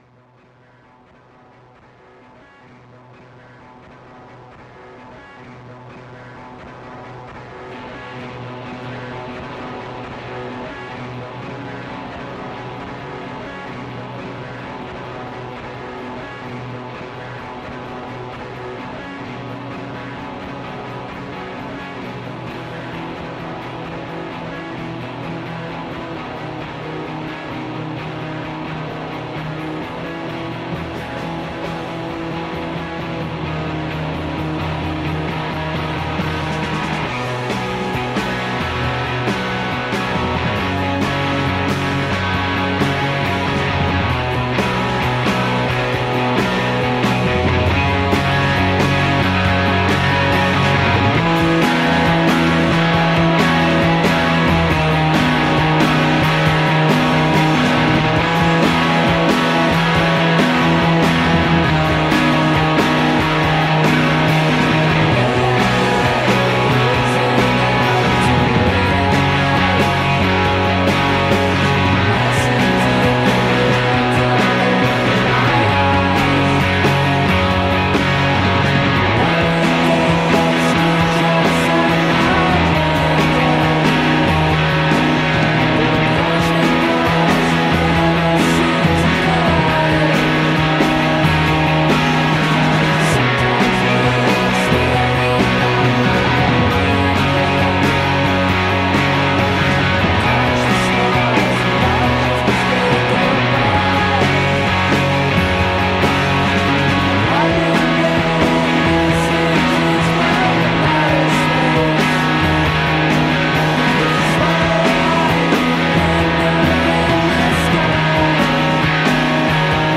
(52:56) Songs about movies, and clips from films, are featured. Including every pop culture reference, chronologically, in Quentin Tarantino films.